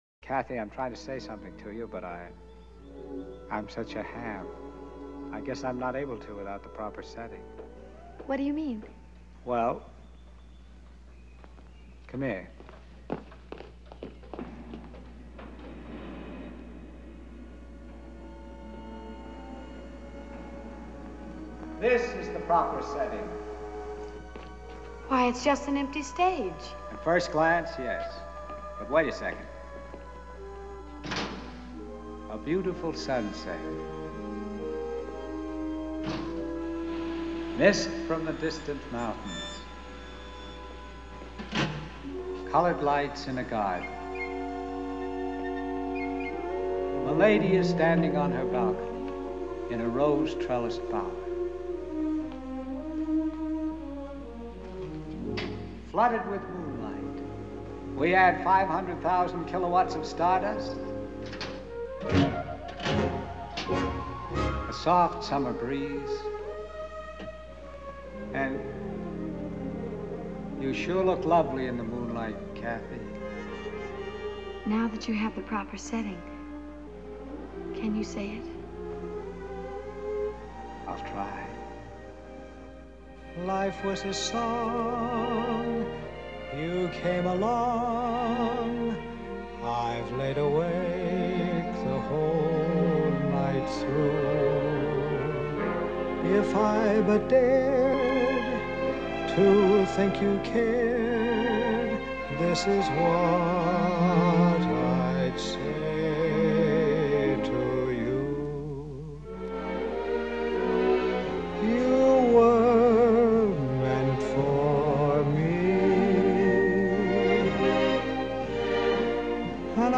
1929   Genre: Soundtrack    Artist